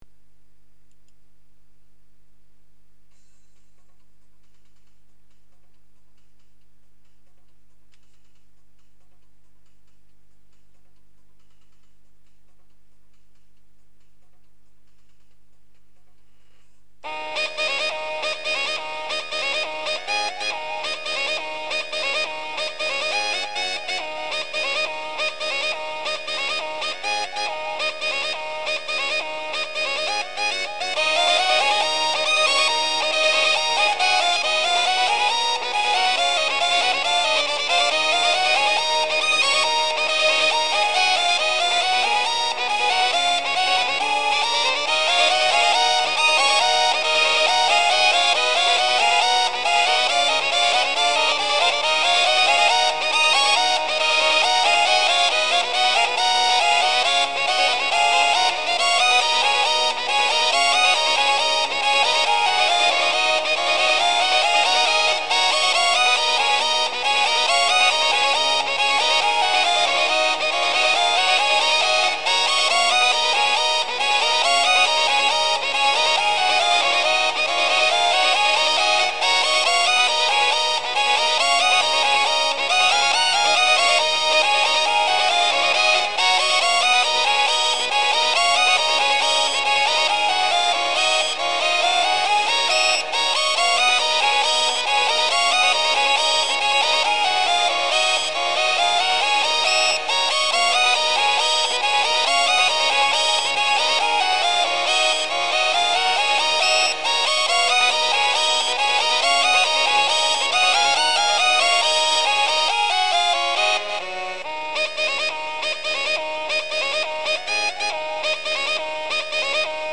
モーターで音源作ってみた。
※初めの16秒わずかにリズムパートが聞こえますが音量を上げないように。